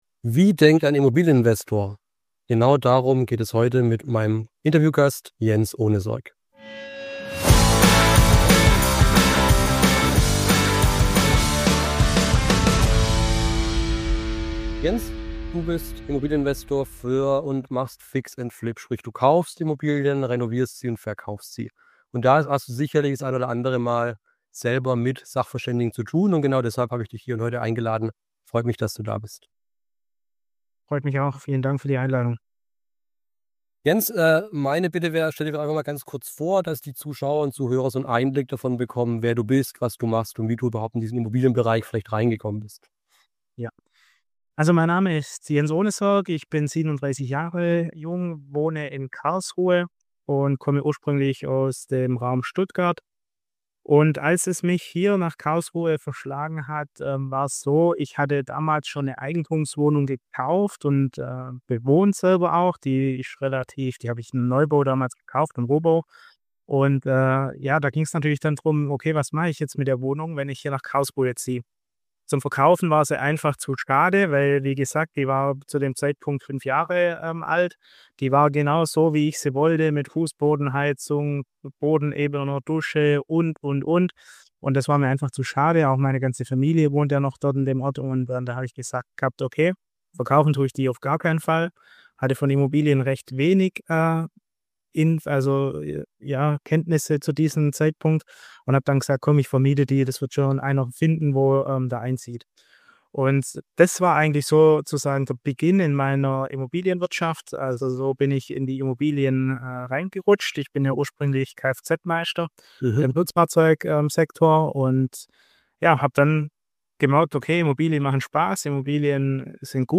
#49 - Wie denkt ein Immobilieninvestor? Interview